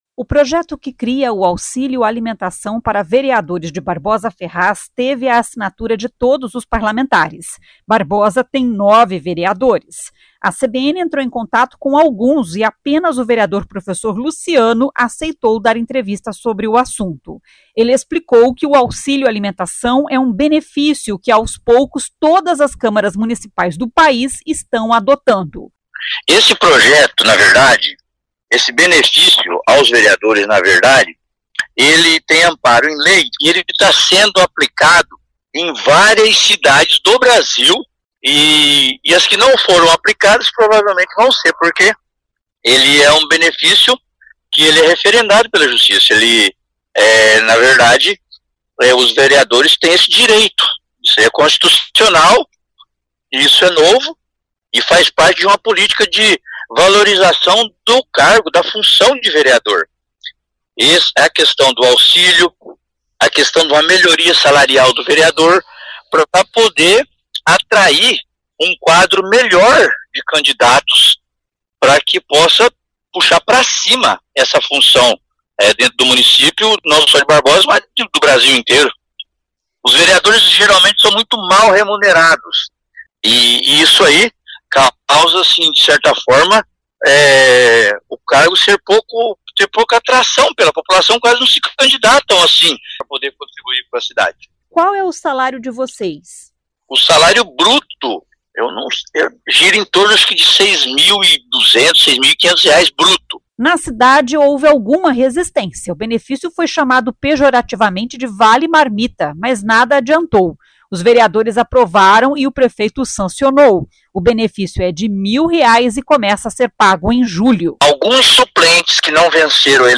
A CBN entrou em contato com alguns e apenas o vereador professor Luciano aceitou dar entrevista sobre o assunto.